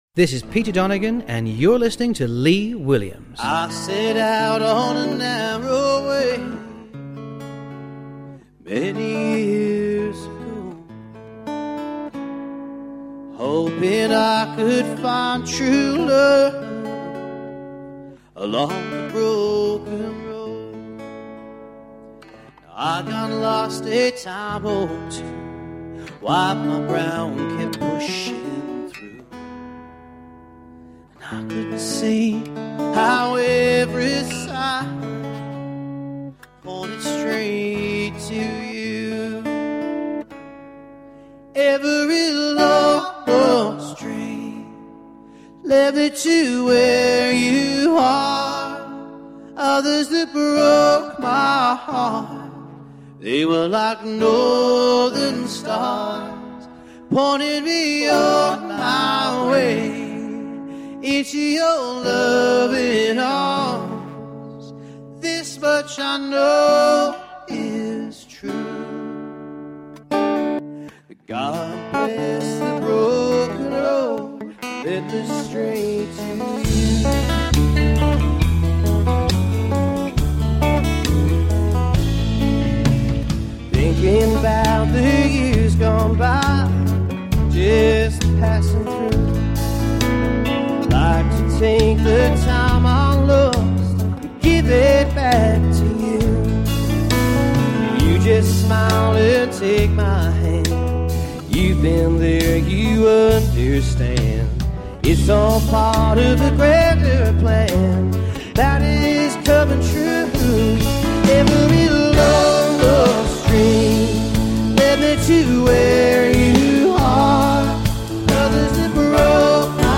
at the recent Weyfest